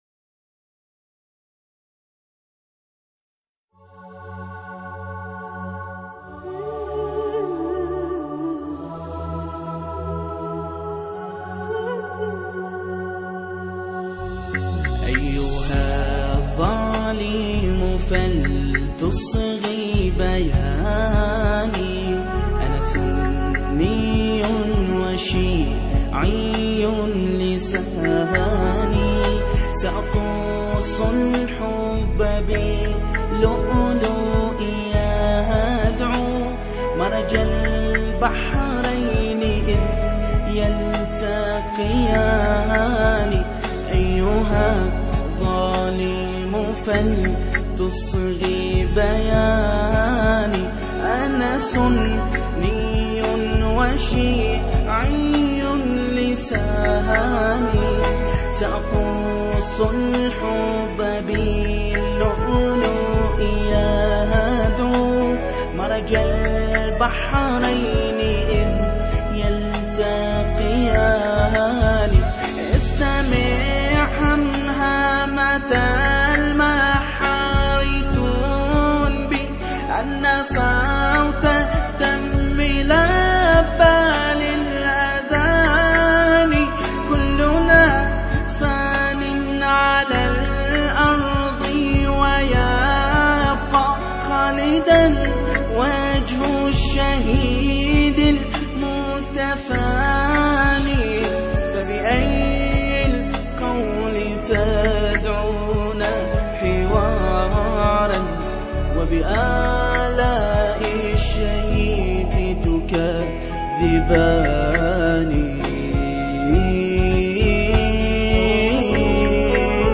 انشودة بحرينية